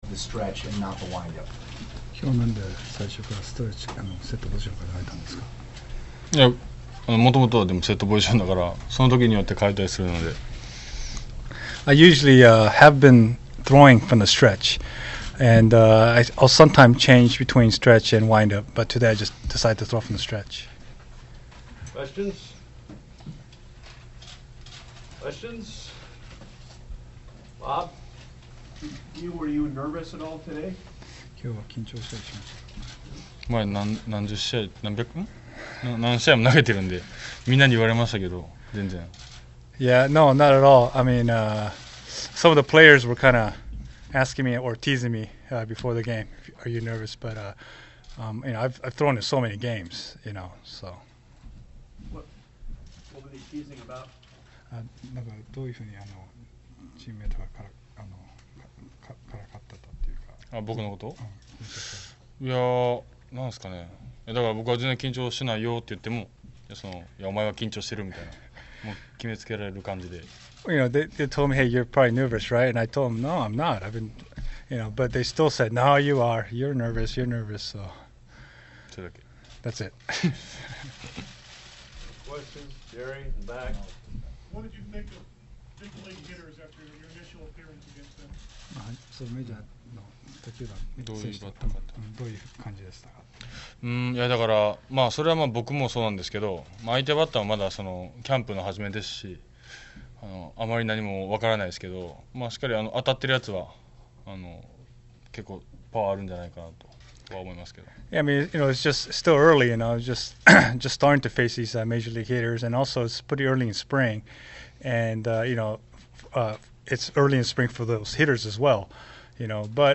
Read Storify: Coverage Timeline of Yu Darvish's First Start As A Rangers LISTEN: Yu Darvish post game press conference (iPad iPhone users click here) Podcast